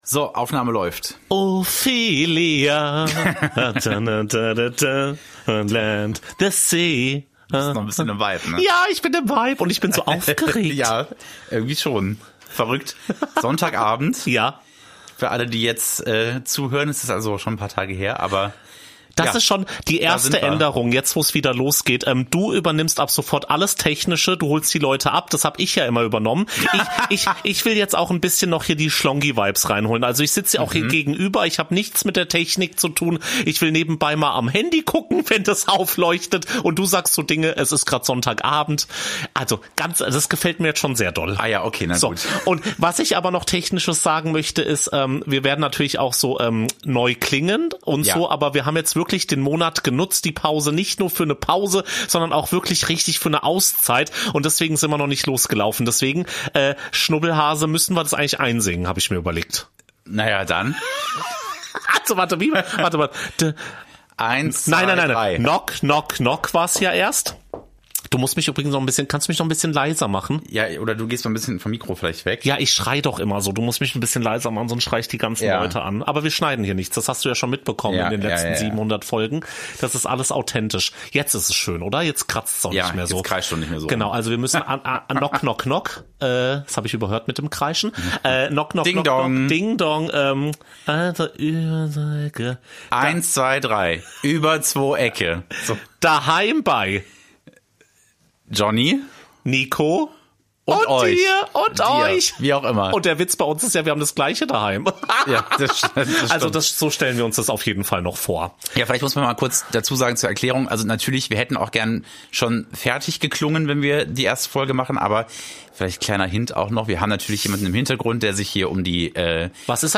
Deshalb wundert euch bitte nicht, Intro und Jingles und all so ein Kram kommen noch!!!!